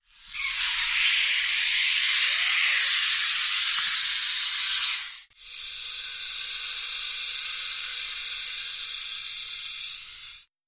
На этой странице собраны реалистичные аудиозаписи: от угрожающего шипения до плавного скольжения по поверхности.
Питон третий вариант